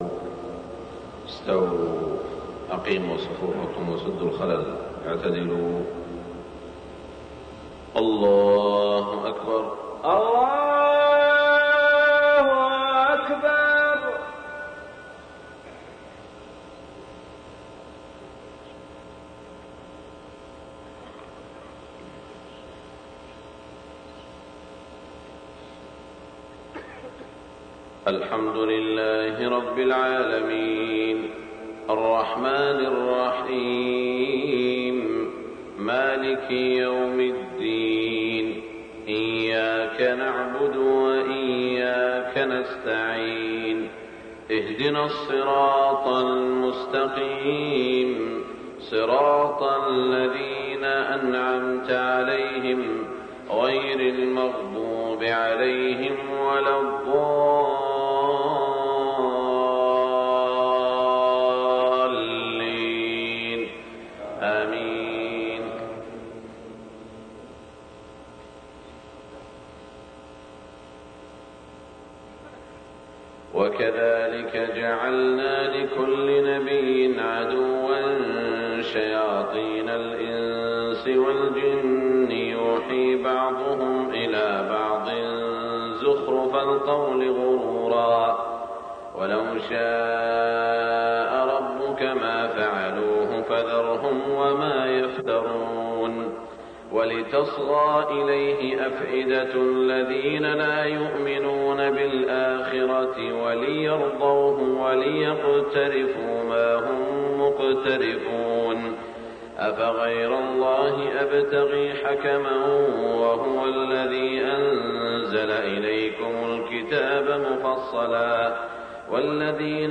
صلاة الفجر 1424 تلاوة من سورة الأنعام > 1424 🕋 > الفروض - تلاوات الحرمين